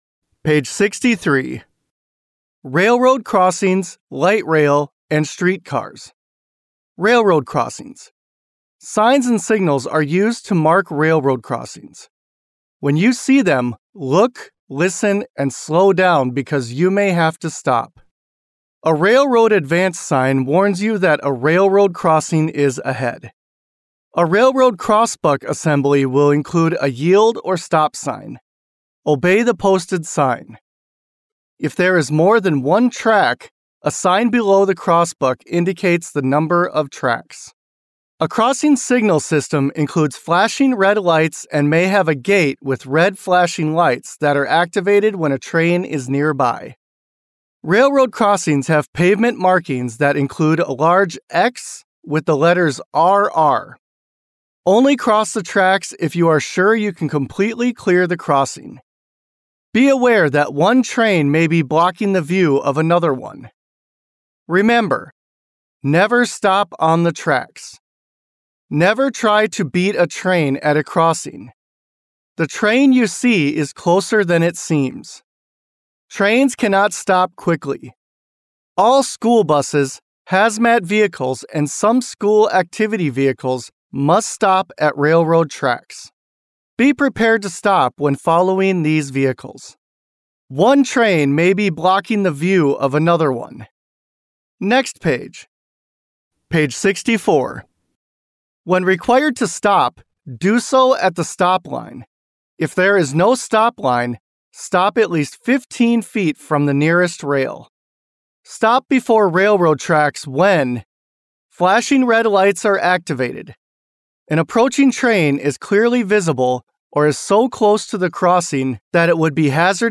Oregon Driver Manual - Audio Version